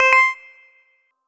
ping.wav